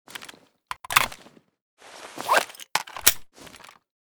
g43_reload.ogg